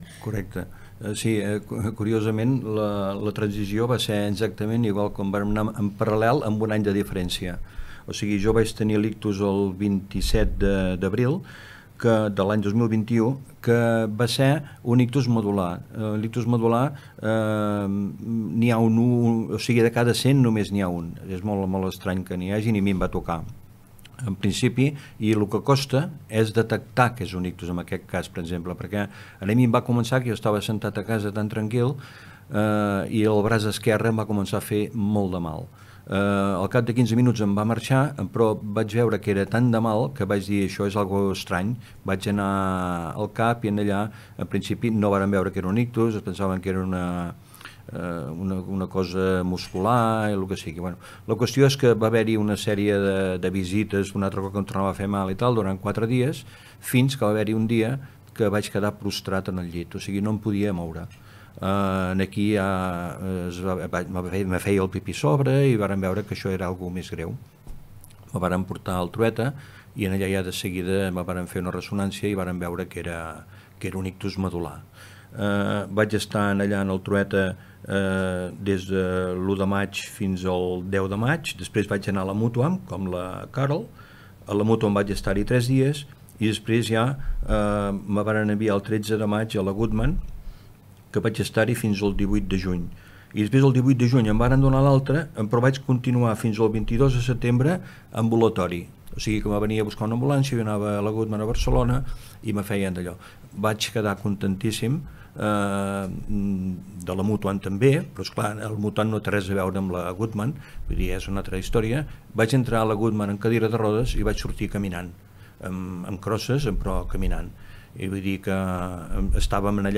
han compartit en una entrevista a Ràdio Capital els seus testimonis personals sobre què suposa patir un ictus i com es pot continuar vivint amb aquesta nova realitat.